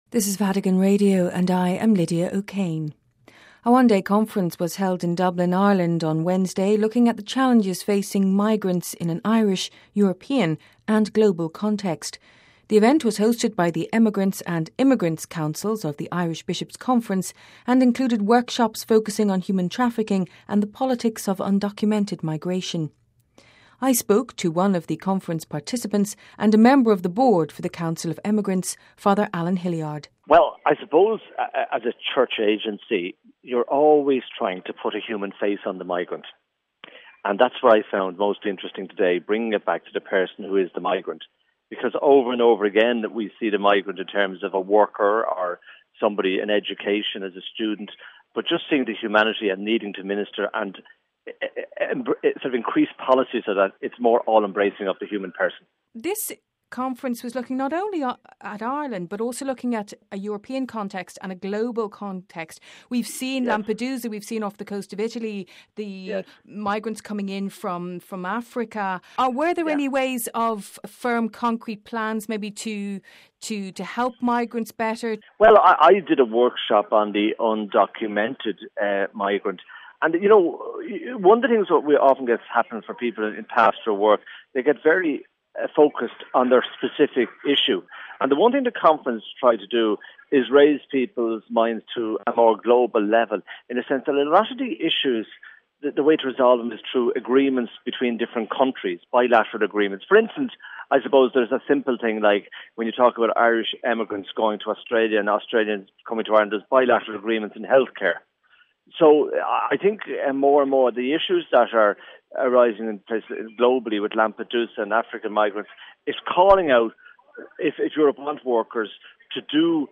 spoke to one of the conference participants